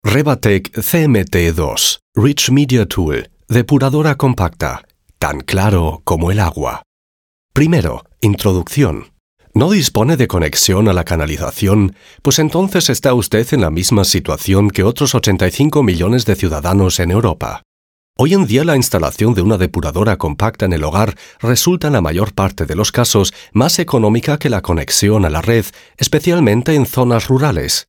Volle, warme, präsente, moderative und rassige spanische Voice-Over-Stimme
kastilisch
Sprechprobe: Industrie (Muttersprache):
Experienced spanish Voice-Over Actor; Full, warm, prominent voice